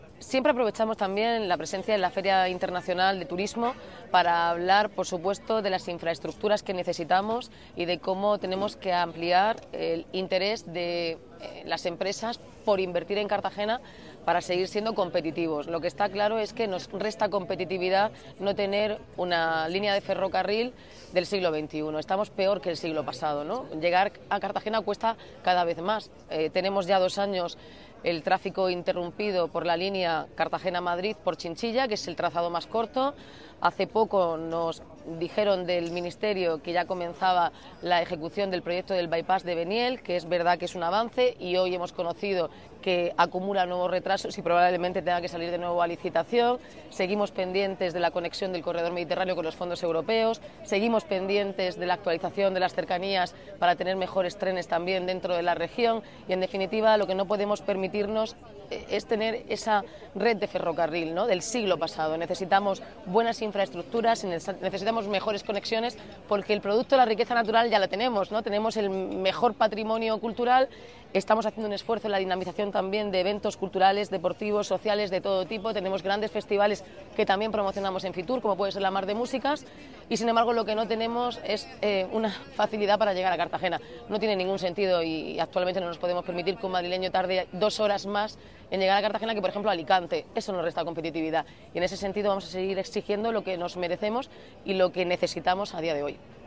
Arroyo ha hecho estas declaraciones en Madrid, donde se encuentra participando en FITUR en la promoción de los recursos y experiencias turísticas de Cartagena, y tras conocer que Adif ha rescindido el contrato para ejecutar el baipás de Beniel, que se suma a los retrasos en el proyecto de integración del AVE en la ciudad y a la pérdida de la conexión con Madrid a través de Chinchilla.